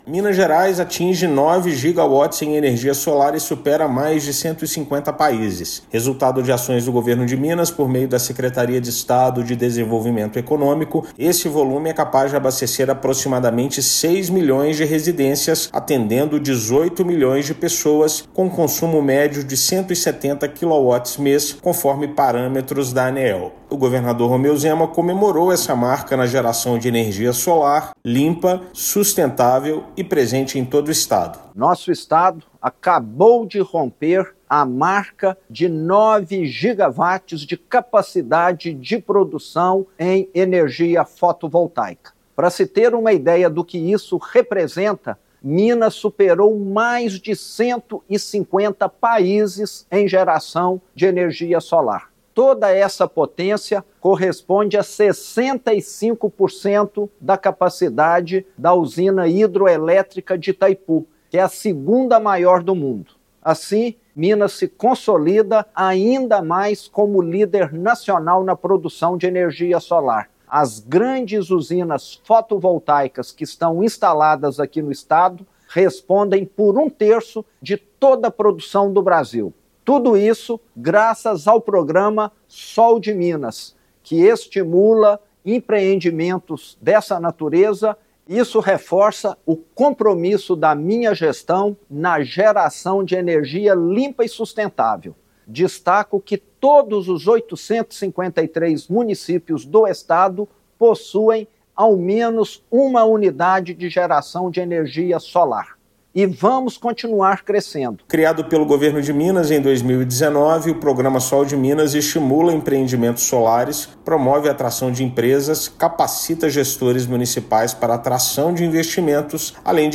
Com ações do Governo de Minas para incentivar o setor, estado reafirma posição como líder nacional na produção da energia limpa e renovável. Ouça matéria de rádio.